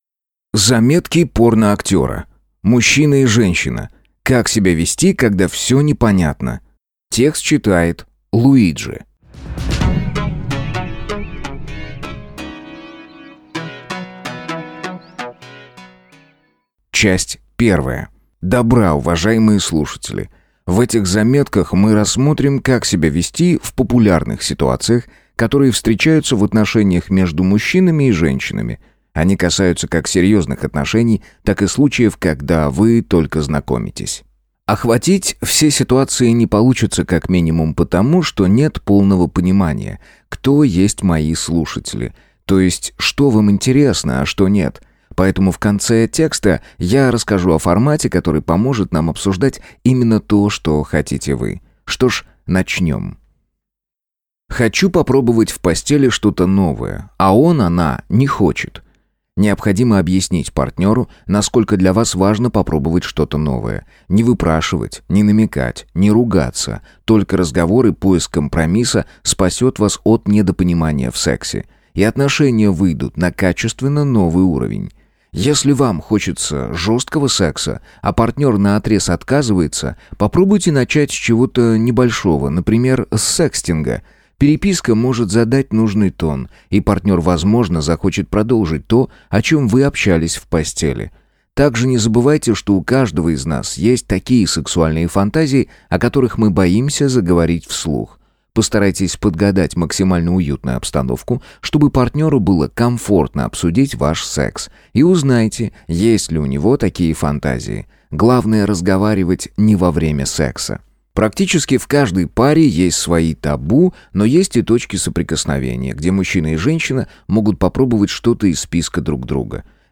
Аудиокнига Мужчина и Женщина. Как себя вести, когда все непонятно. Часть 1 | Библиотека аудиокниг